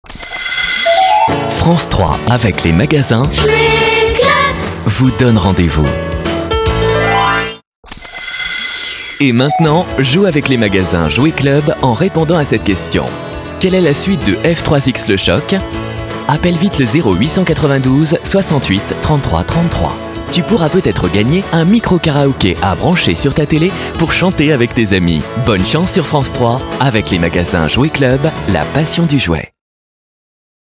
JOUE CLUB chaleureux - Comédien voix off
Genre : voix off.